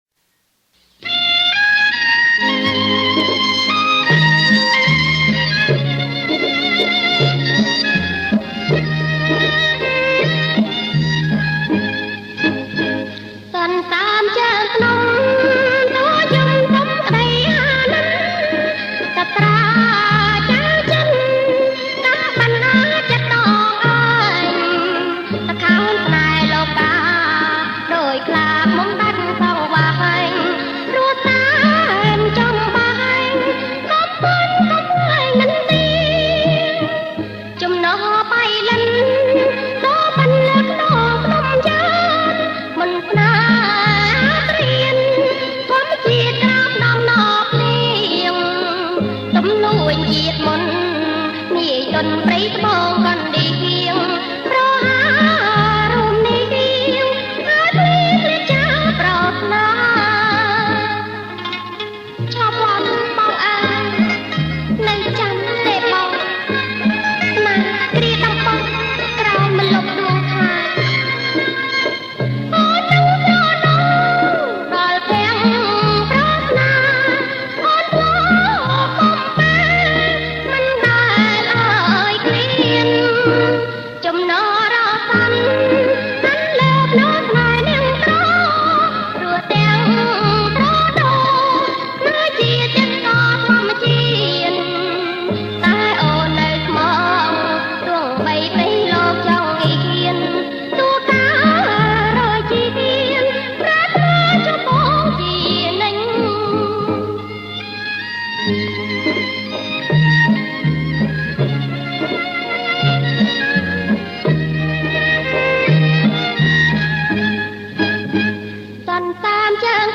• ប្រគំជាចង្វាក់ Bolero Folk